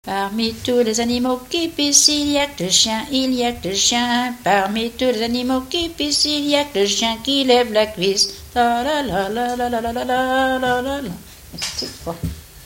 danse : mazurka
Genre brève
Pièce musicale inédite